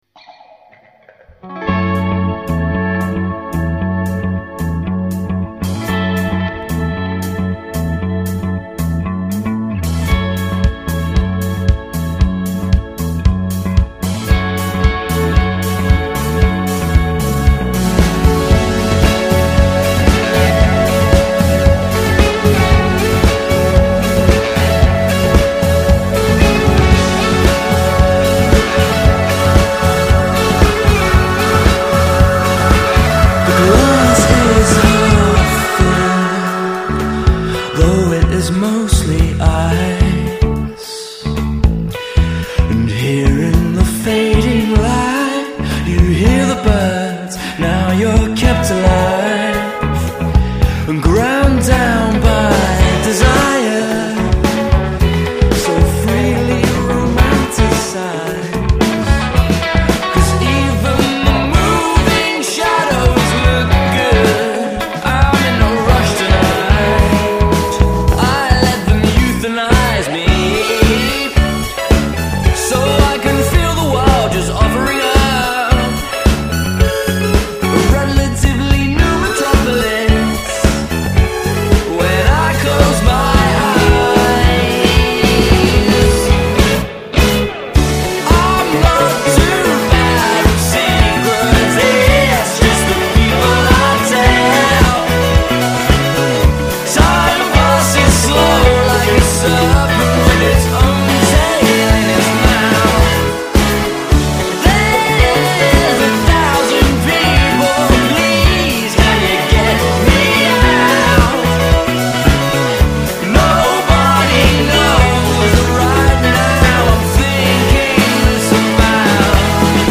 Brimful of grooving attitude
icy pop